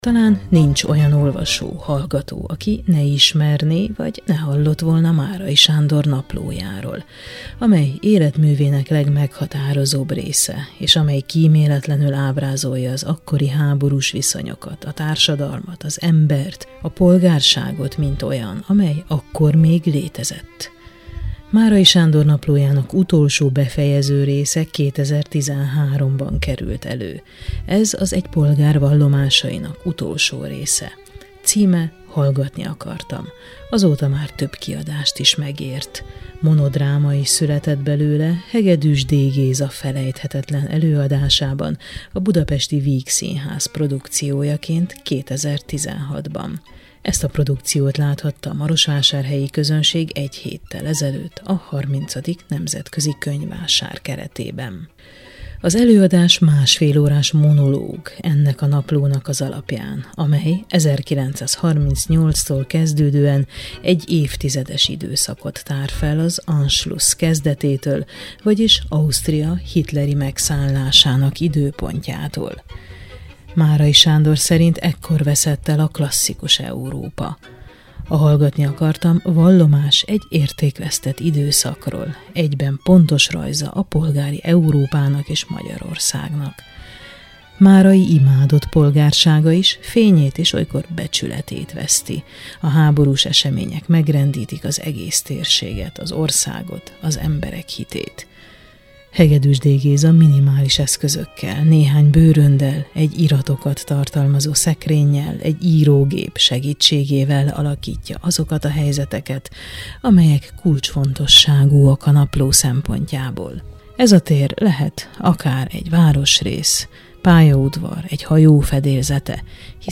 Beszélgetés Hegedűs D. Gézával A Hallgatni akartam című produkcióról